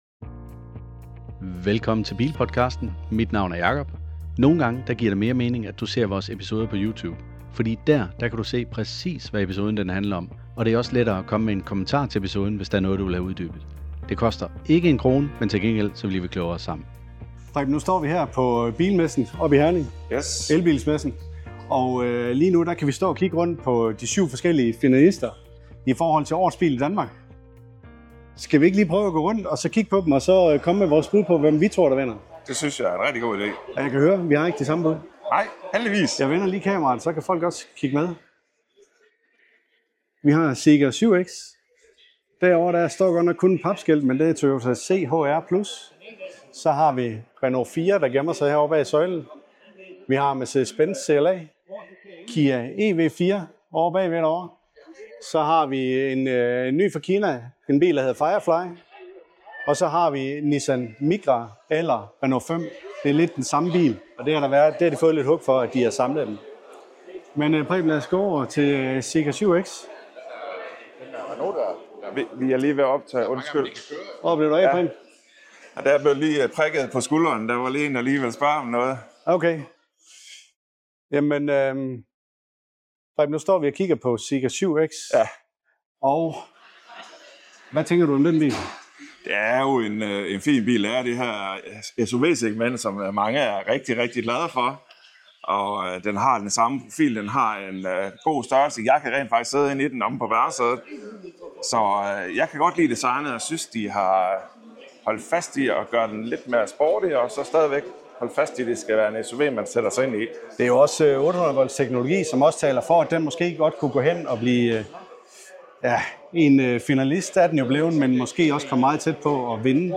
Hvilken bil bliver kåret som Årets Bil i Danmark 2026? Vi går tæt på alle syv finalister på elbilsmessen i Herning og afslører vores personlige bud på vinderen!